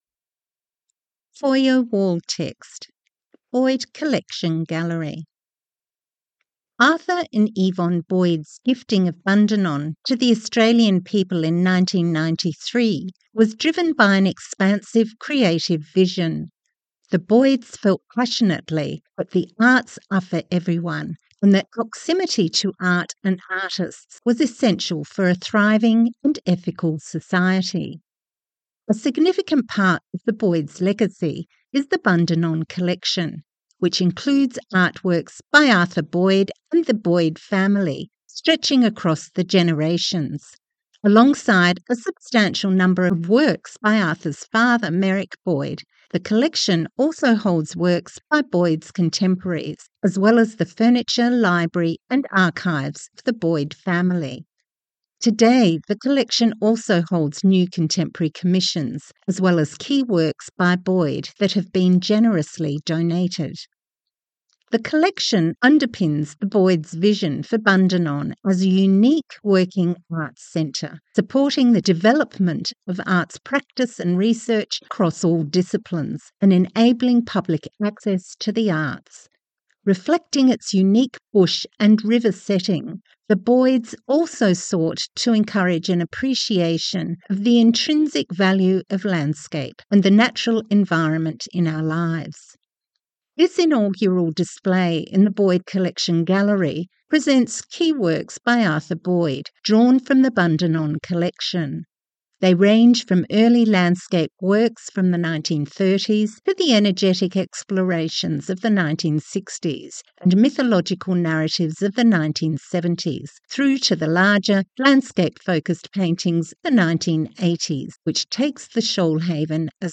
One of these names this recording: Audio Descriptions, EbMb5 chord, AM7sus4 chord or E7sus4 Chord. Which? Audio Descriptions